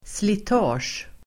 Ladda ner uttalet
slitage substantiv, wear [and tear] Uttal: [slit'a:sj] Se slita Böjningar: slitaget Synonymer: nötning Definition: slitning, nötning Exempel: utsättas för slitage (exposed to wear) Sammansättningar: däckslitage (wear to tyres)